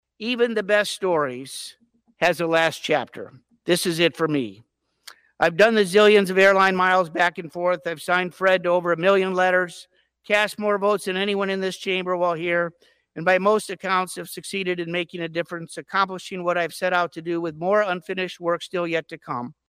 (WASHINGTON, D.C.) – The dean of Michigan’s Congressional delegation emotionally announced Tuesday morning on the U.S. House floor he will be retiring at the end of this year.